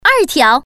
Index of /hunan_master/update/12813/res/sfx/common_woman/